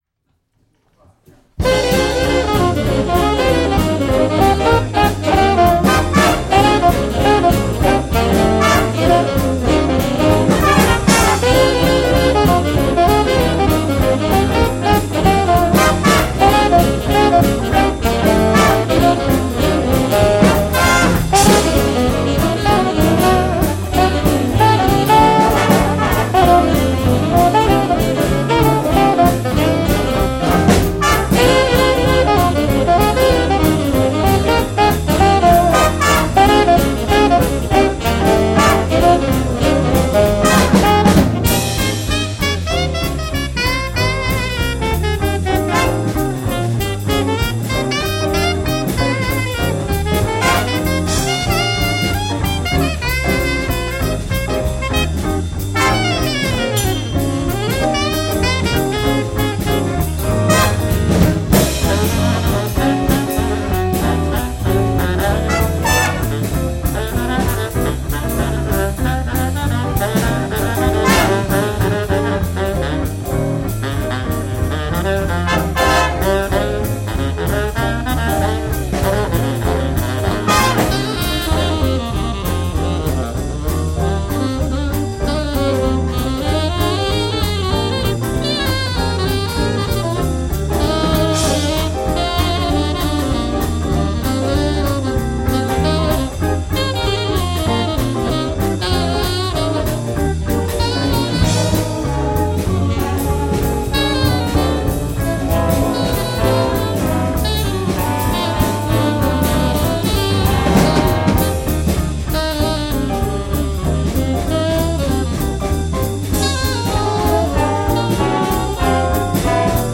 Soirée Jazzalauna 2011 – Samedi 19 Novembre 2011
Soirée spéciale SAXOPHONE
Ray Big Band – Jazzalauna